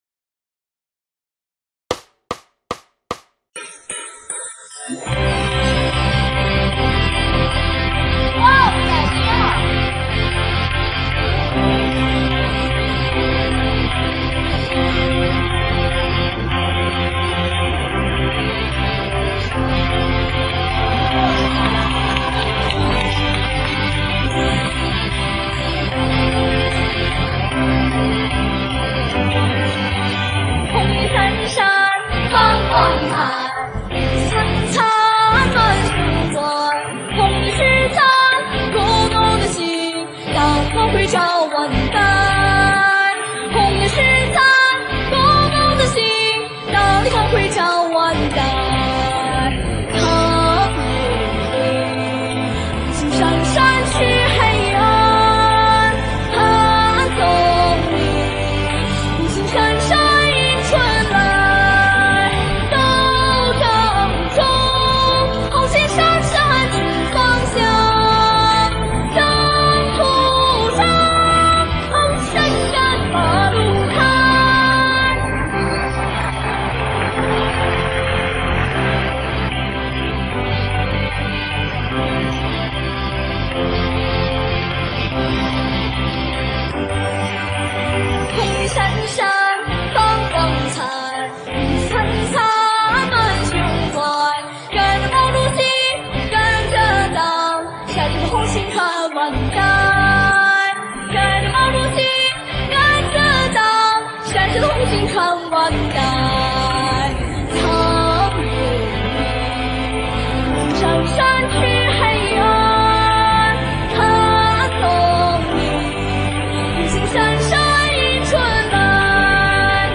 节拍：4/4，每分钟150拍